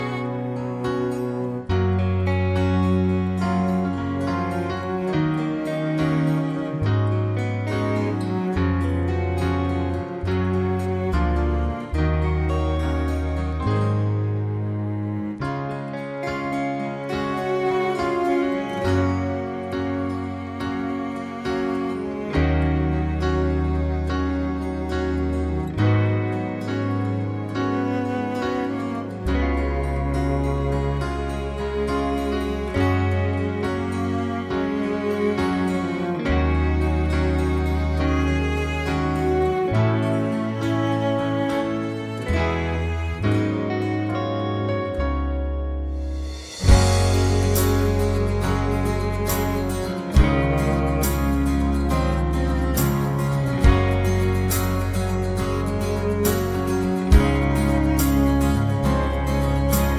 Musicals